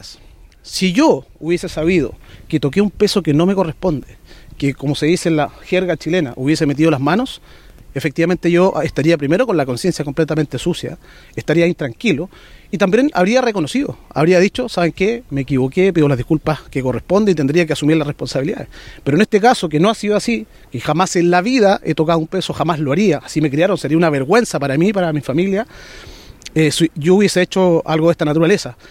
Previo al comienzo de la audiencia de formalización, el diputado desaforado habló con la prensa, donde insistió en su inocencia.